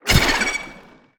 Sfx_creature_trivalve_death_swim_01.ogg